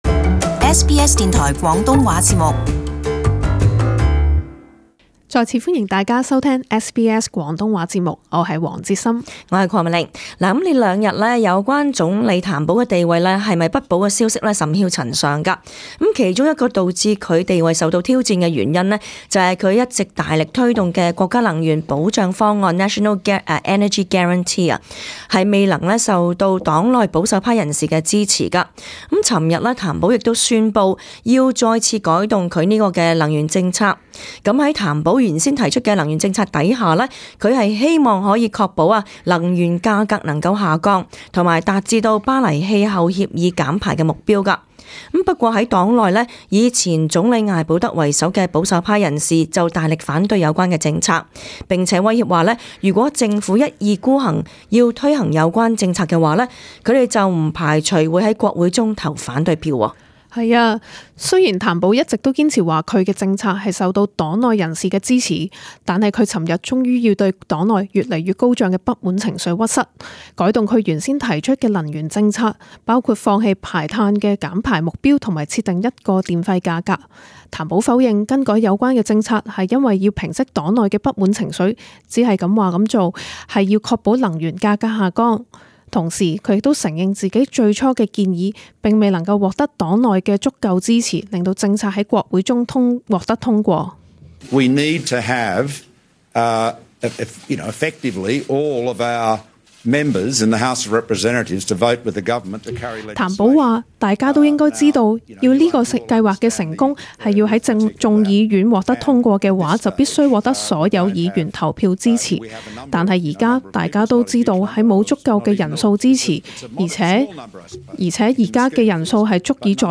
【時事報導】譚保再改能源政策